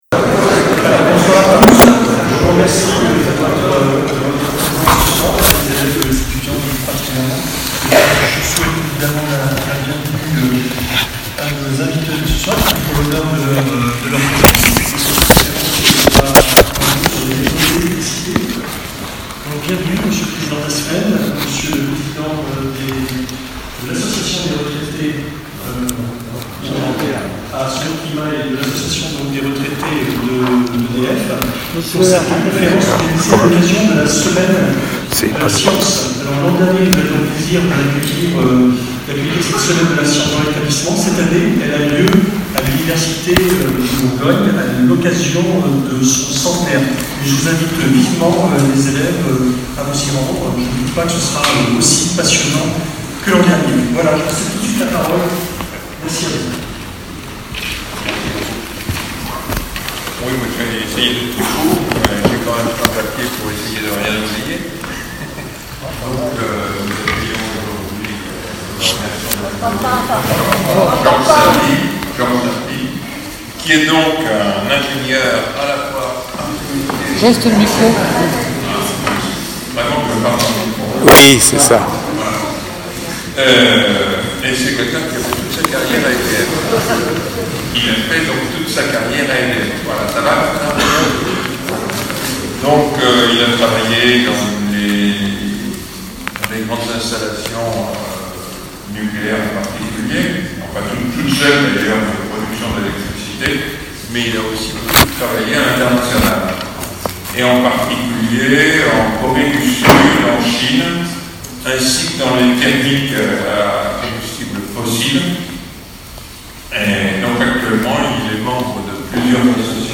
Conférence Réseaux électriques